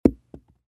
Звуки падения гильзы
Гильза легла на ковер